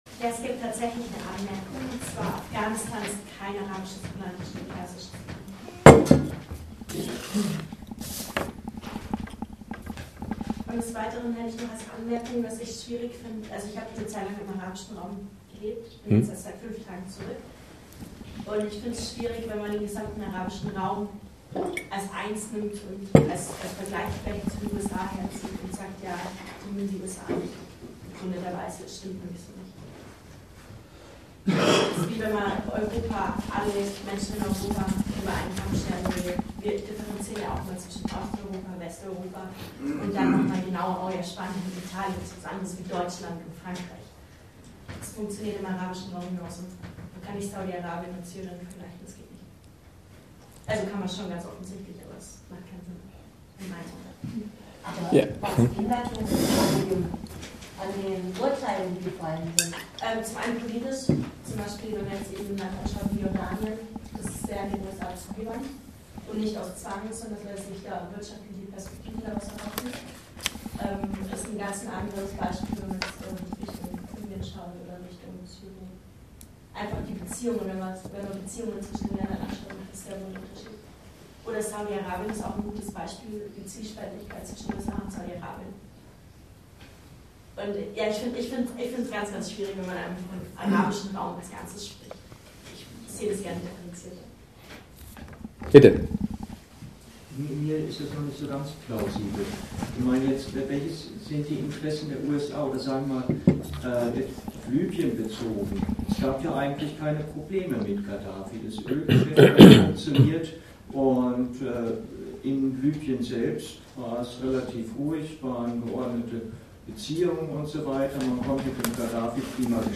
Ort Regensburg